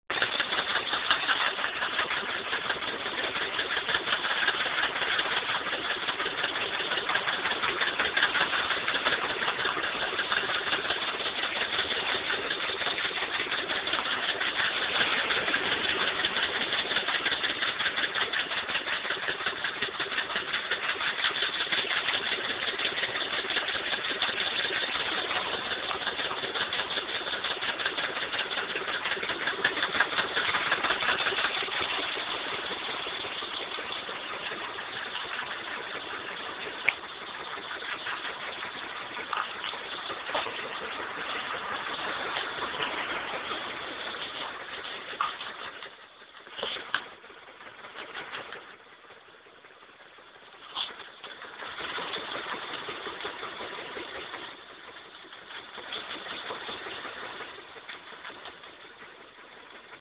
Bruit moteur
Ca fait "clac , clac, .." . Pendant l'enregistrement ca a fini par s'arr?ter d'ailleurs.
Quand on accél?re , ça s'entend moins parce que c'est couvert par le bruit de l'accélération mais ça reste l?.